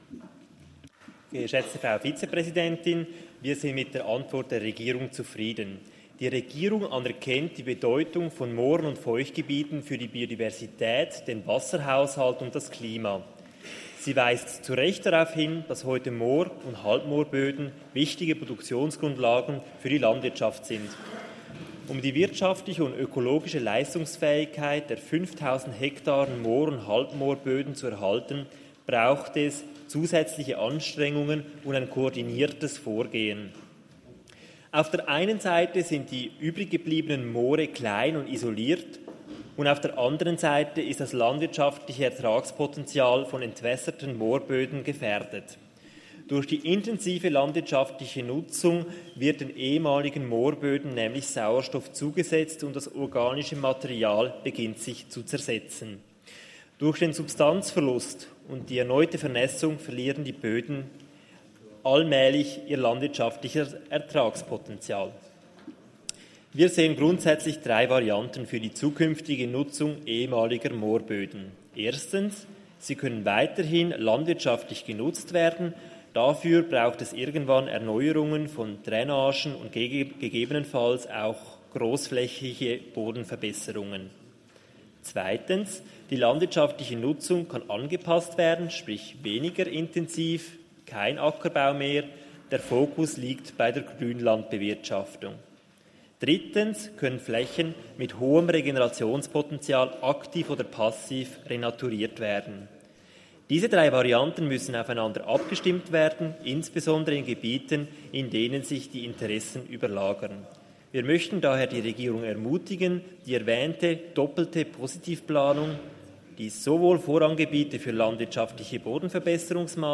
Session des Kantonsrates vom 27. bis 29. November 2023, Wintersession